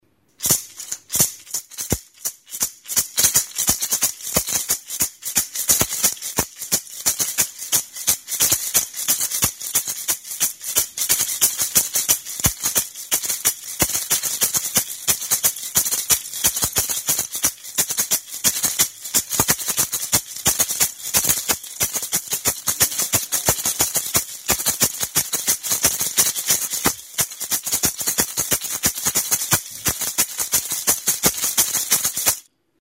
Idiófonos -> Golpeados -> Maracas / sonajeros
Grabado con este instrumento.
Kokoen azal gogorrarekin eta zurezko kirtenarekin egindako bi maraka. Astintzerakoan, barruan dituzten hazi aleek hotsa ematen dute.